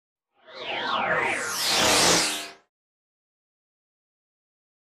Synth Spaceship Approach, In And By 2. More Dynamic By Than FX 73.